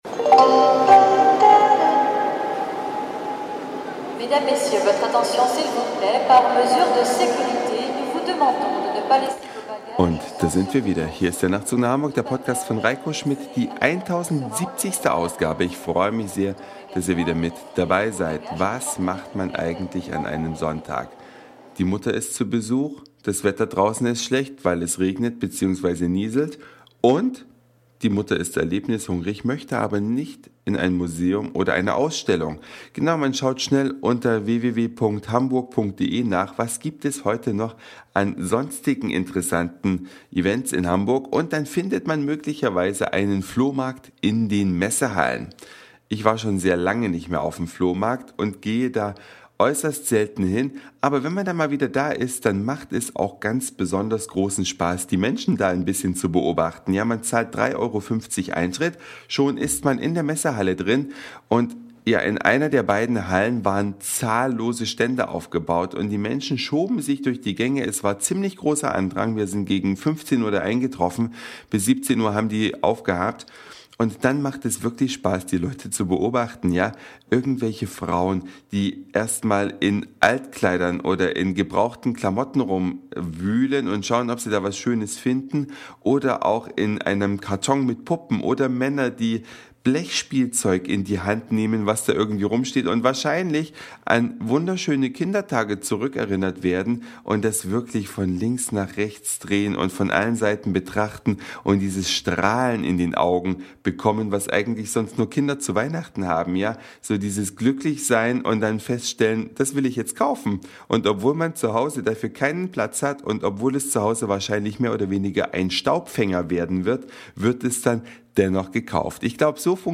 Eine Reise durch die Vielfalt aus Satire, Informationen, Soundseeing und Audioblog.
Flohmarkt in den Hamburger Messehallen, gefunden unter